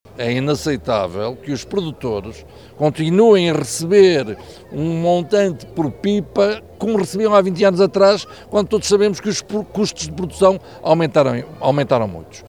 O ministro da Agricultura referiu que é necessário valorizar o produto e dar rendimento aos agricultores: